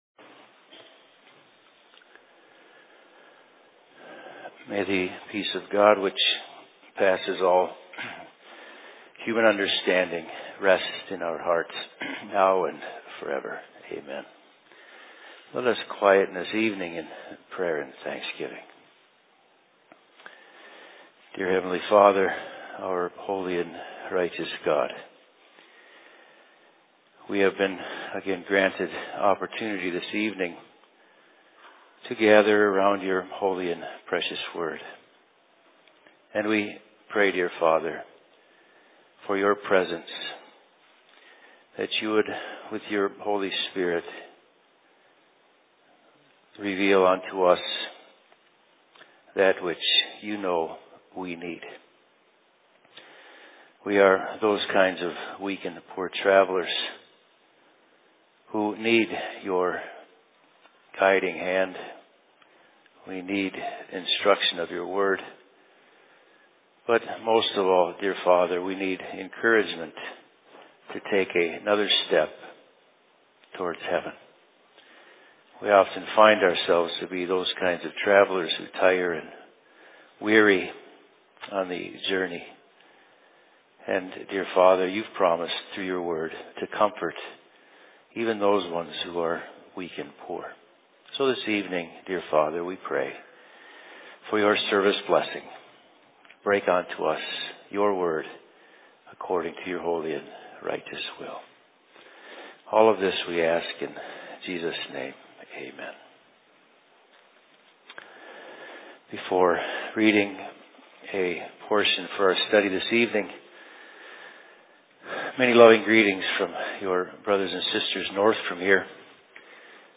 Worship Services/Sermon in Phoenix 14.09.2014